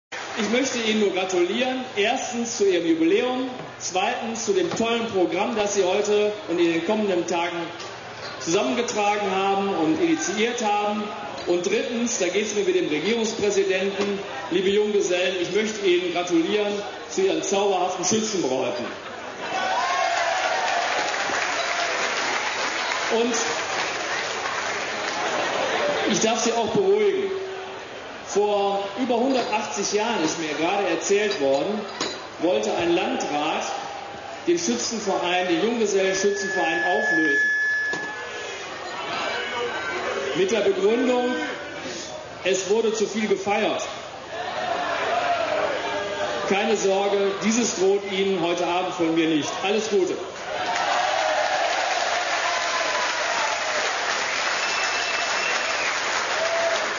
Rede des Landrates Kubendorff (222 kB, 0:56 min)